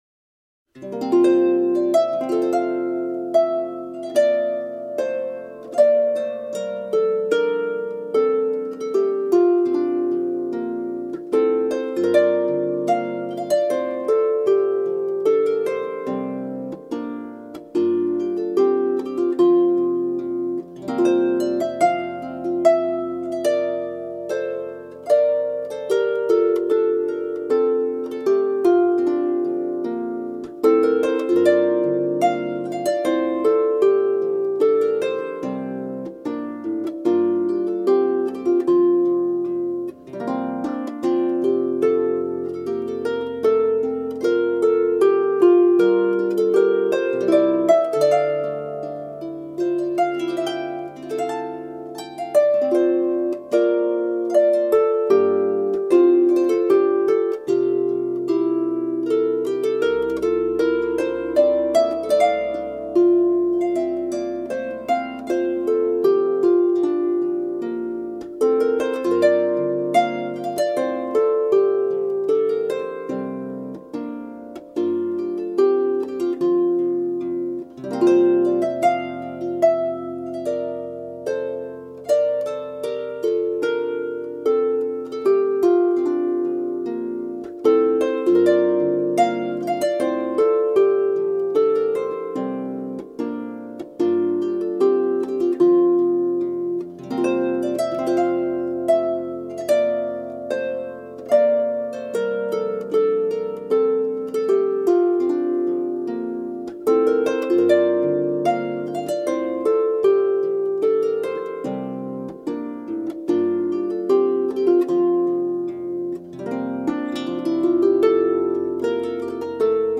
• Comhaltas Ceoltóirí Éireann
Harp